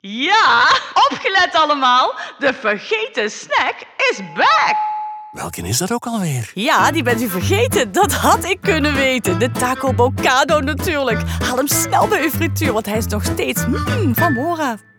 De Vergeten Snack op de radio!
3 verschillende radiospots, waarin de enige echte Cora van Mora, de luisteraars zal verleiden om de Mora Vergeten Snacks te ontdekken bij hun lokale frituur,  zullen te horen zijn op onder andere deze radiostations: QMusic, JOE, MNM, Studio Brussel,… in Vlaanderen.